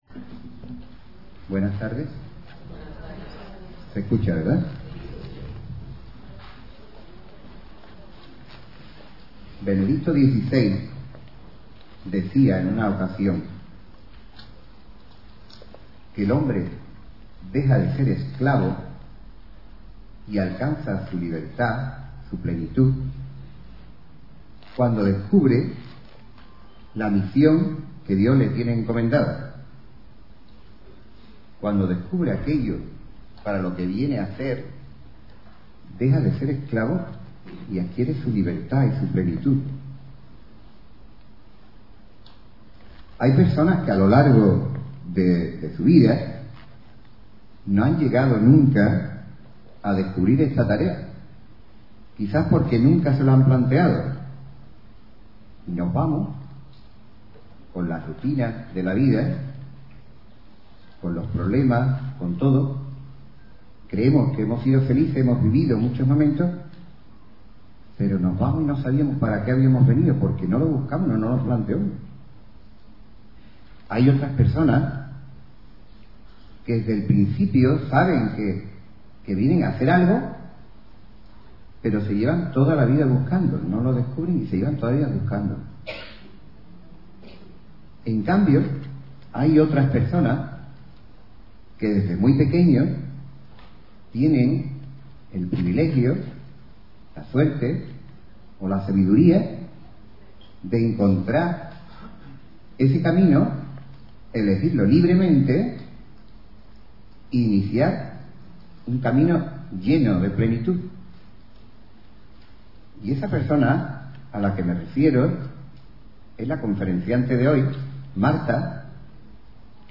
Audio conferencias